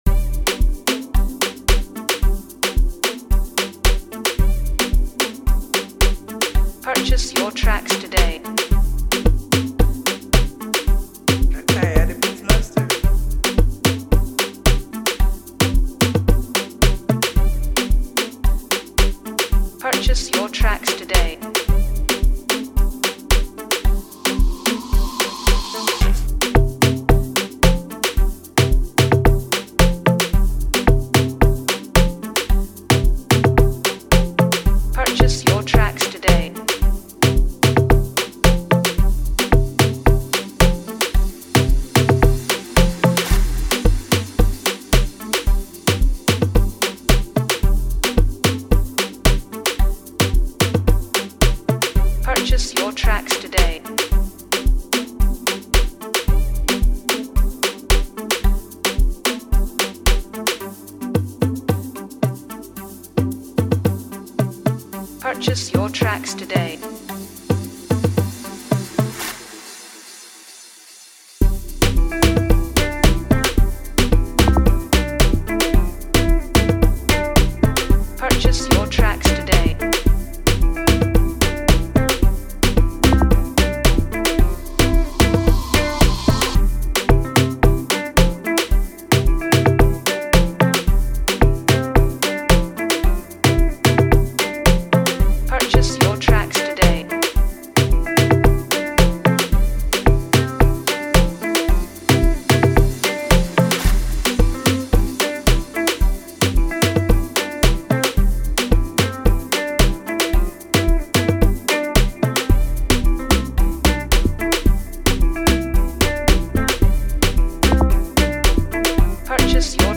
Dive into the infectious rhythm